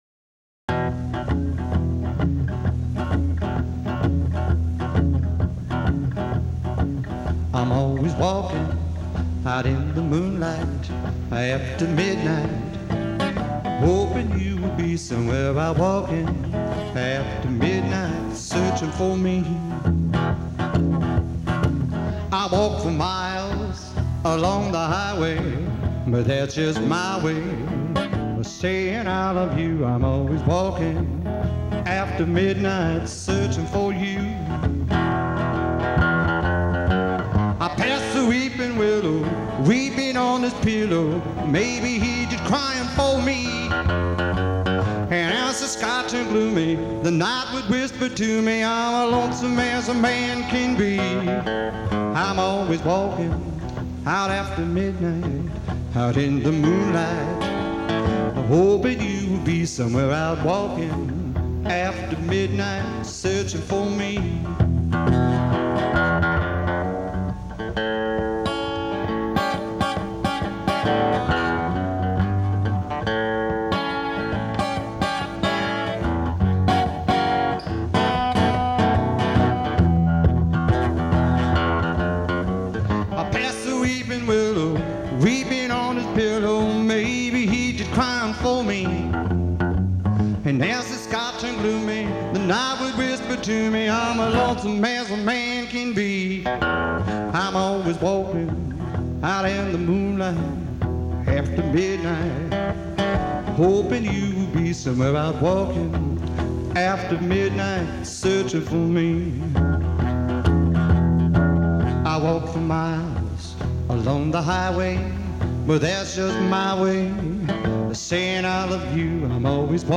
Category Rock/Pop
Studio/Live Live